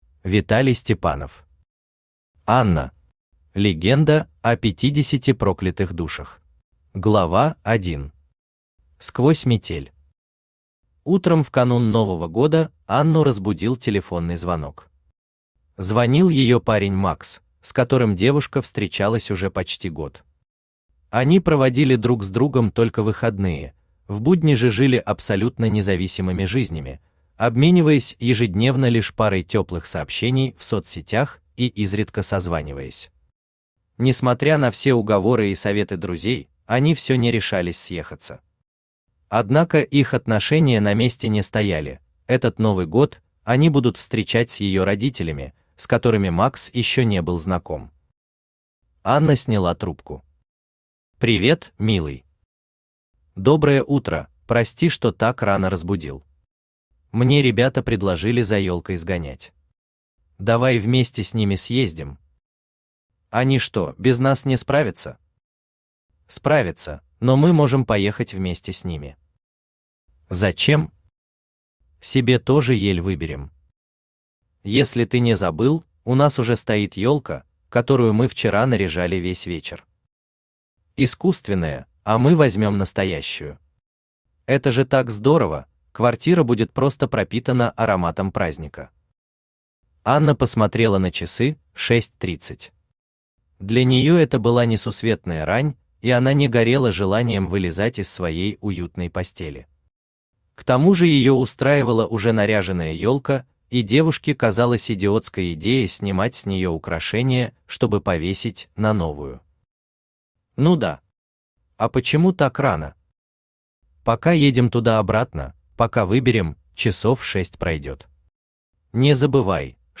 Аудиокнига Анна. Легенда о пятидесяти проклятых душах | Библиотека аудиокниг
Легенда о пятидесяти проклятых душах Автор Виталий Андреевич Степанов Читает аудиокнигу Авточтец ЛитРес.